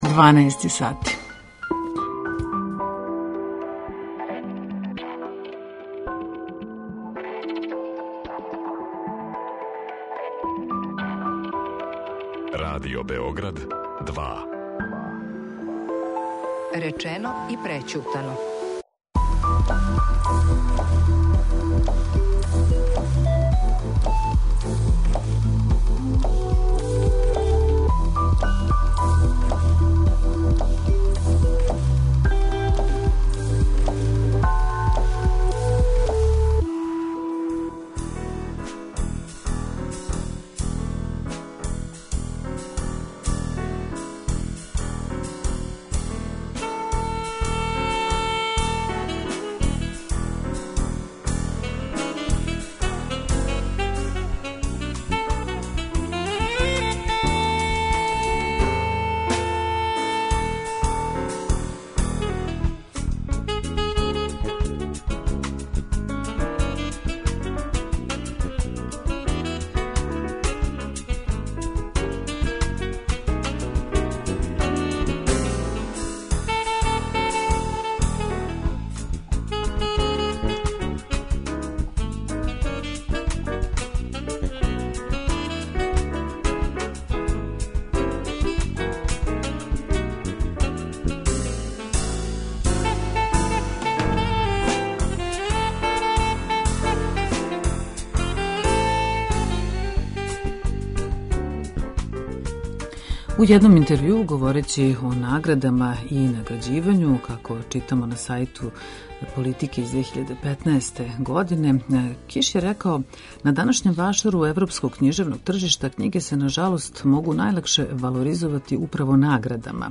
Са нама уживо - новинарке и уреднице које дуги низ година прате културу